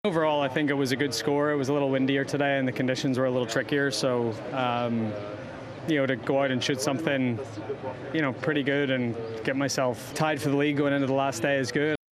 He tells Sky Sports News, he's in a strong position.